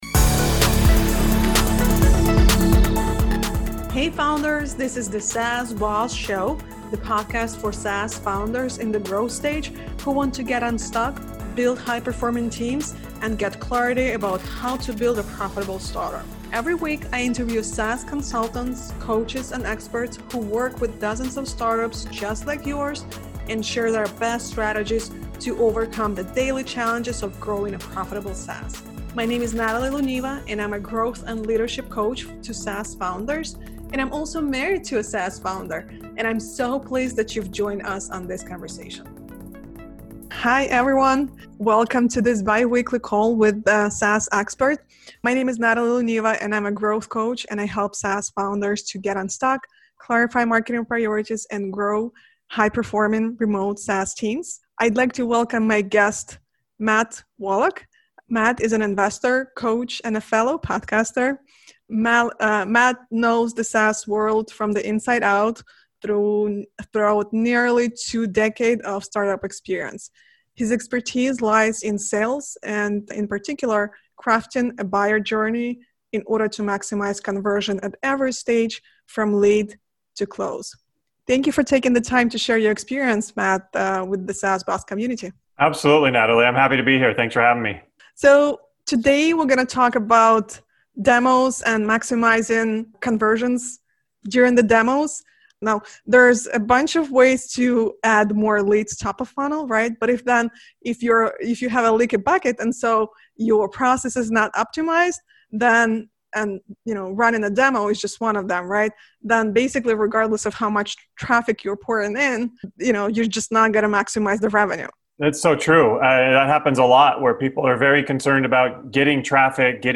This is a recording of the live training that was live streamed to SaaS Boss community.